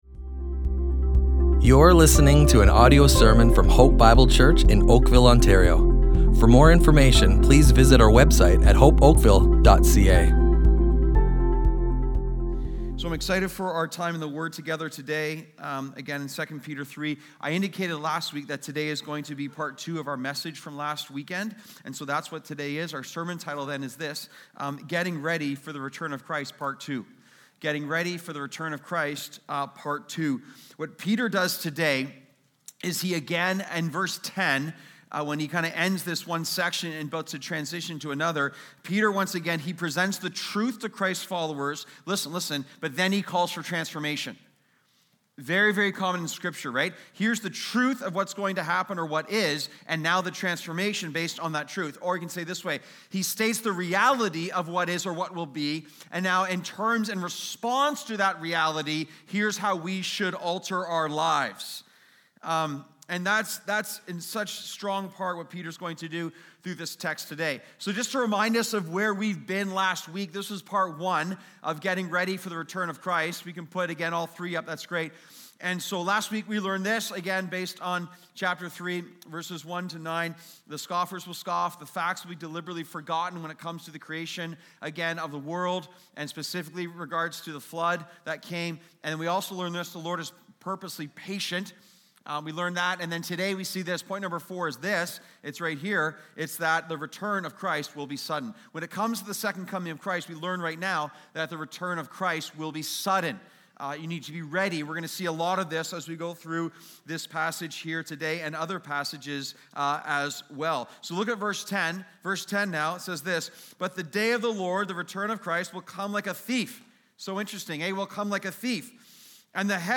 Hope Bible Church Oakville Audio Sermons Character, Corruption, and the Second Coming // Getting Ready for the Return of Christ!